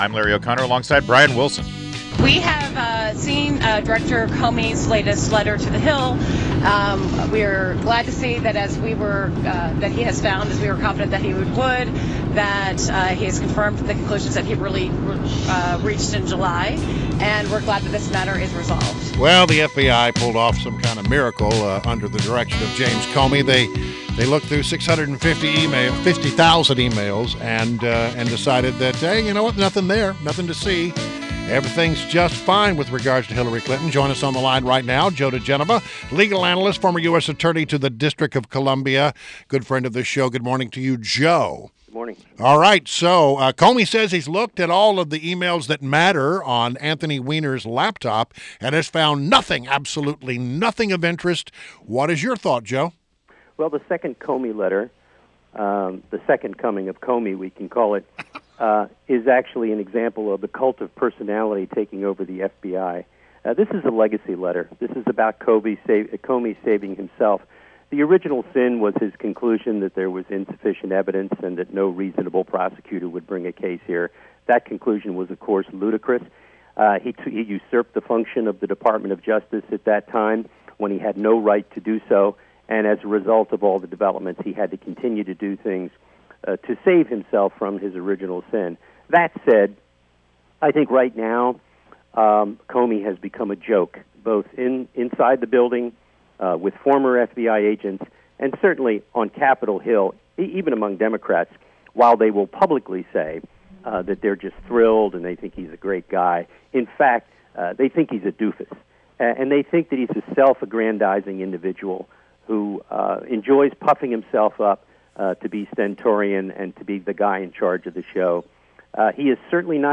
WMAL Interview - JOE DIGENOVA - 11.07.16
INTERVIEW – JOE DIGENOVA – legal analyst and former U.S. Attorney to the District of Columbia